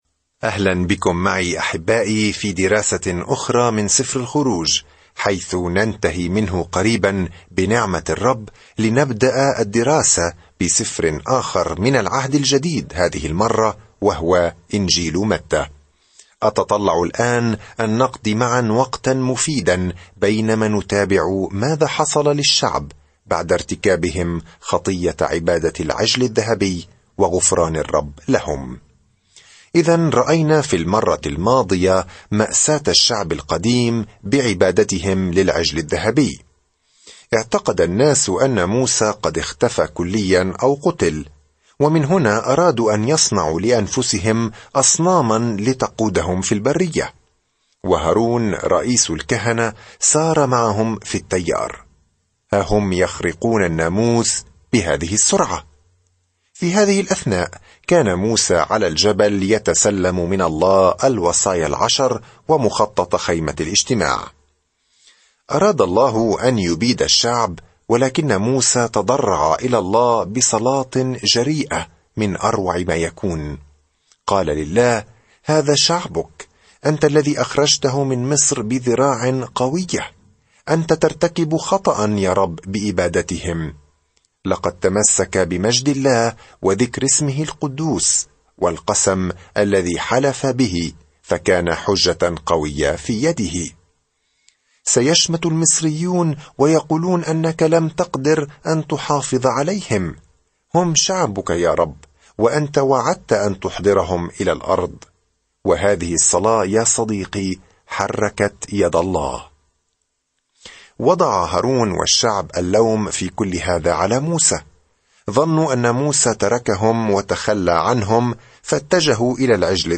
الكلمة اَلْخُرُوجُ 7:33-23 اَلْخُرُوجُ 34 يوم 29 ابدأ هذه الخطة يوم 31 عن هذه الخطة يتتبع سفر الخروج هروب إسرائيل من العبودية في مصر ويصف كل ما حدث على طول الطريق. سافر يوميًا خلال سفر الخروج وأنت تستمع إلى الدراسة الصوتية وتقرأ آيات مختارة من كلمة الله.